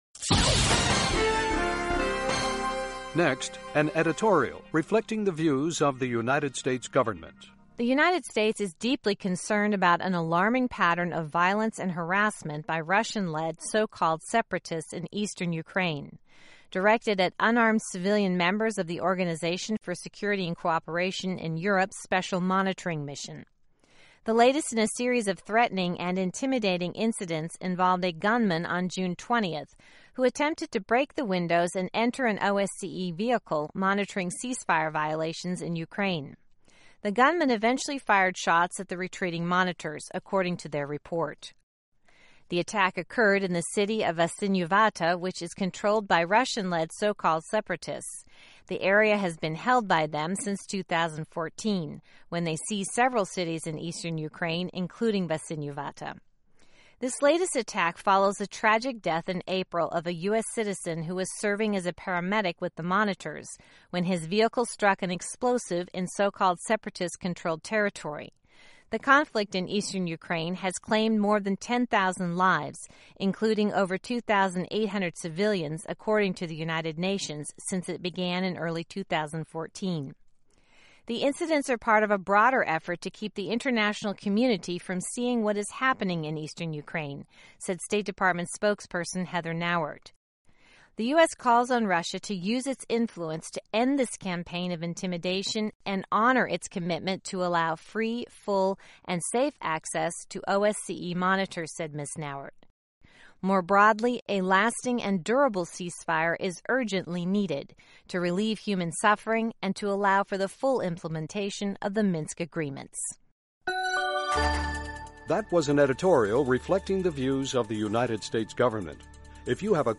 by Editorials on Voice of America
Reflecting the Views of the U.S. Government as Broadcast on The Voice of America